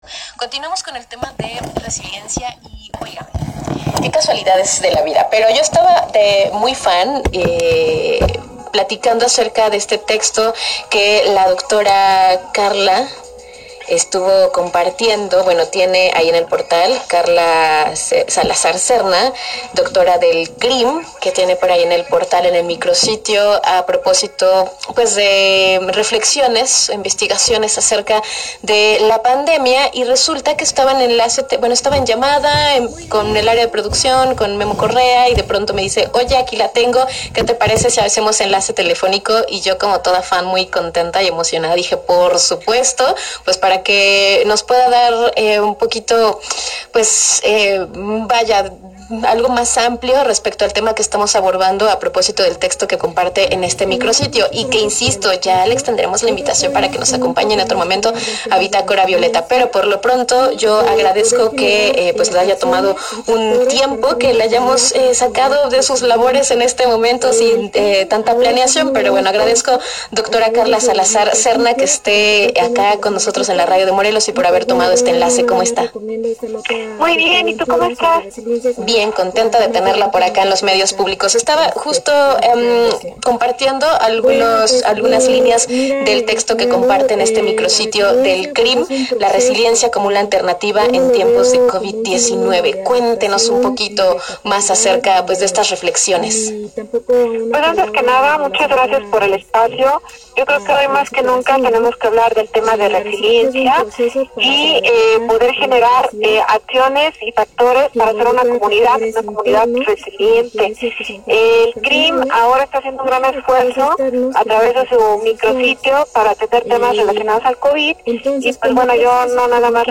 Entrevista